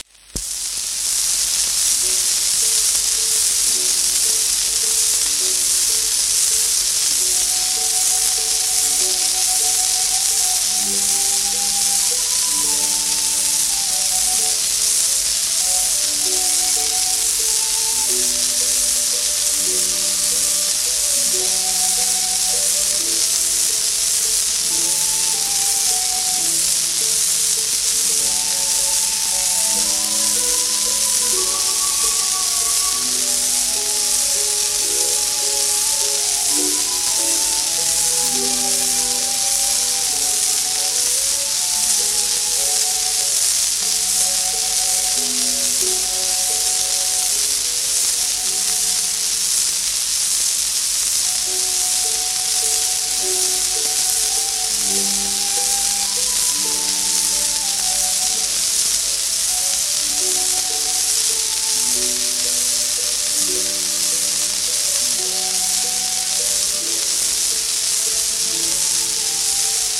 盤質A- *シェラックノイズ
1930年と言へば電気録音も新技術としては普及した頃ですが、なぜかレベルの低い録音となっています。